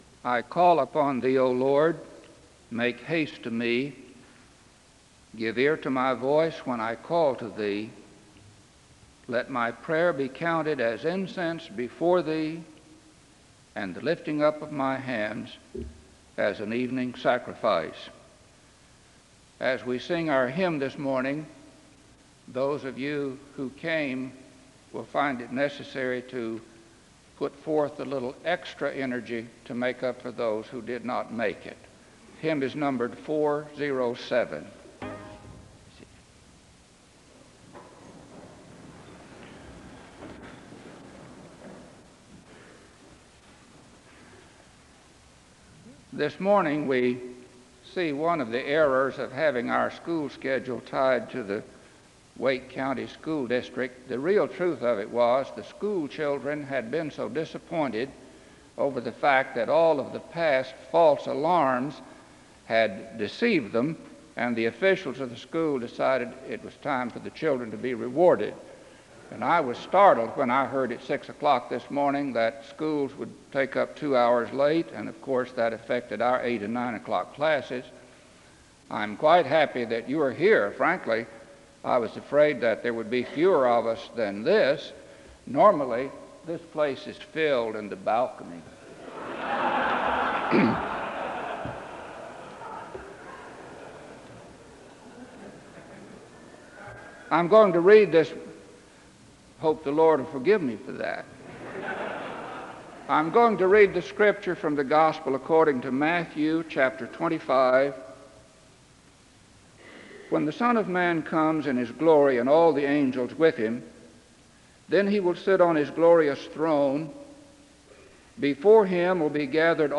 Chapel opens in prayer and an introduction for song (0:00-0:40).
Introduction of chapel speaker, Senator Robert Morgan, is given (3:58-5:21). Choir leads in singing (5:21-10:12). Senator Morgan begins by reflecting on the separation of church and state and states that Christians should be involved in politics (10:12-14:29).